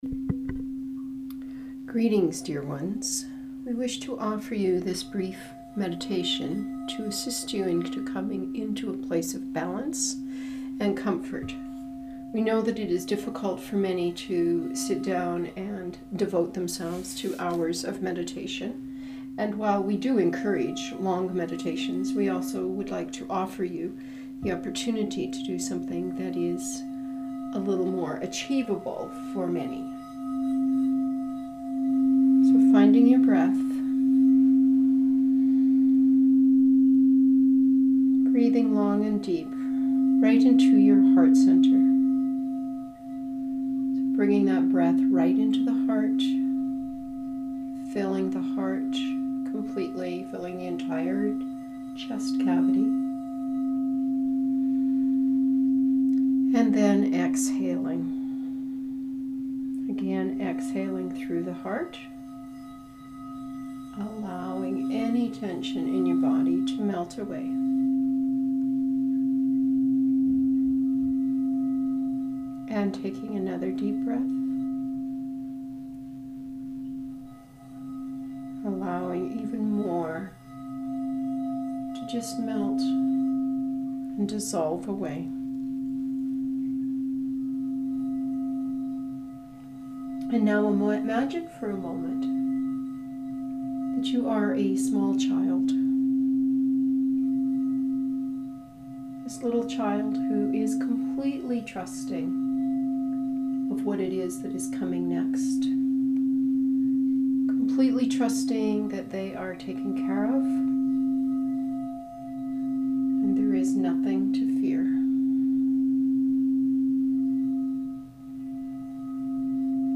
Please see end of post for brief guided meditation.
Meditation-for-Wholeness-April-2020.mp3